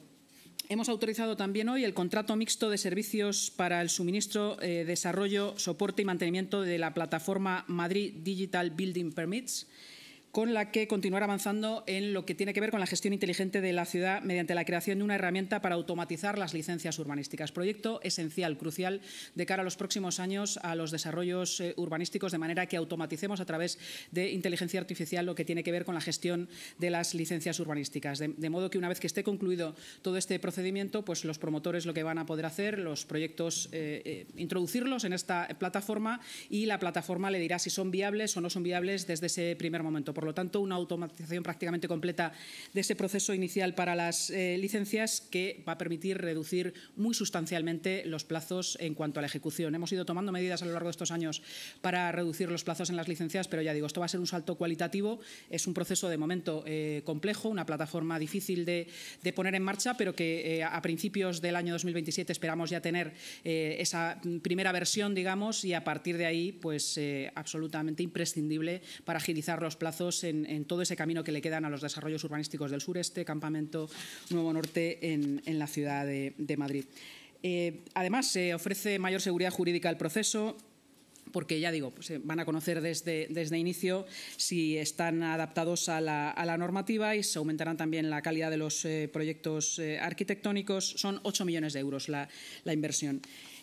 Nueva ventana:La vicealcaldesa de Madrid y portavoz municipal, Inma Sanz: